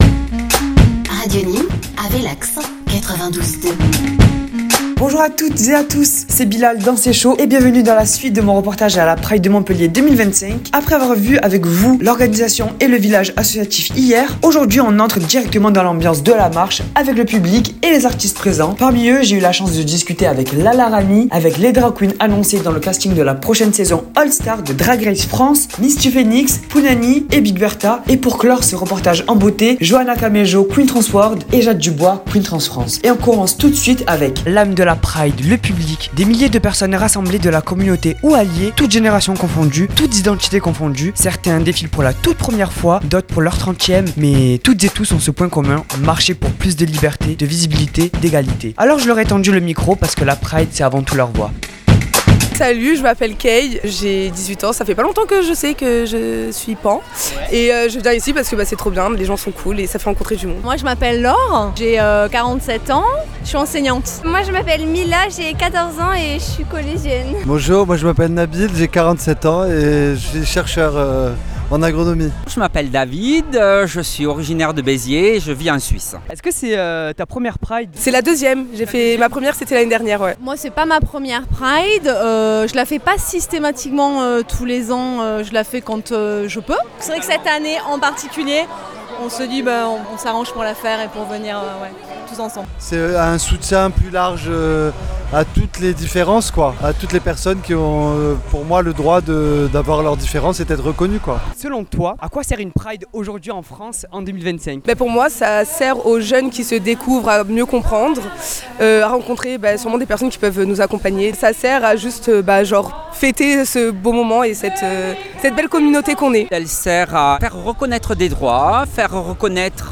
Il tend le micro aux voix du public, aux messages, et aux témoignages.